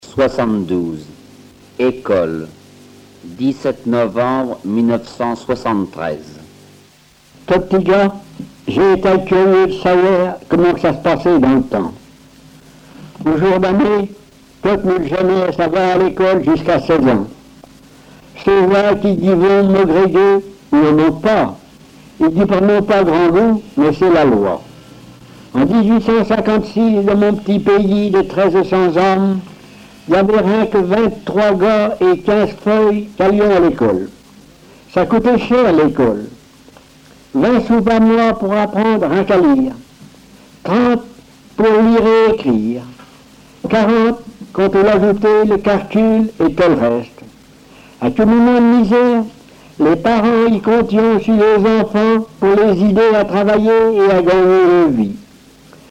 Localisation Saint-Hilaire-de-Chaléons
Langue Patois local
Genre récit